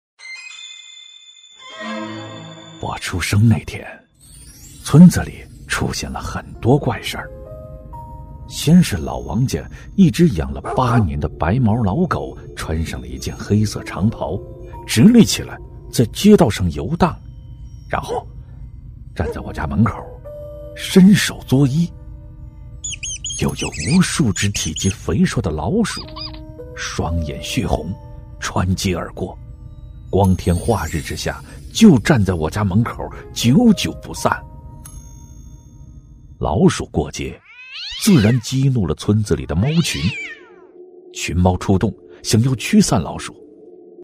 男71-小说配音-恐怖小说身临其境
男71-稳重底蕴 大气浑厚
男71-小说配音-恐怖小说身临其境.mp3